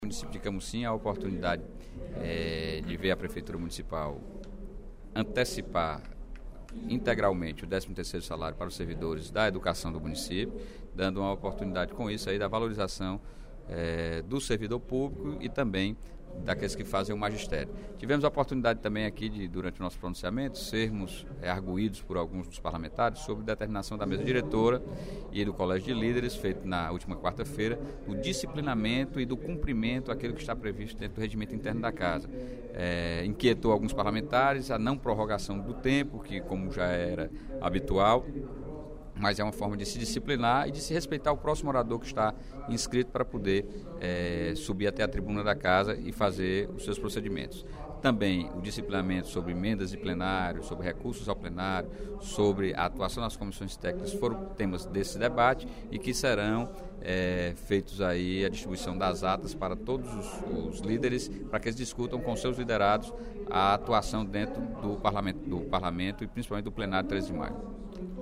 O deputado Sérgio Aguiar (Pros) informou, durante o primeiro expediente da sessão plenária desta sexta-feira (06/11), que o município de Camocim antecipou o pagamento do 13º salário dos profissionais do magistério.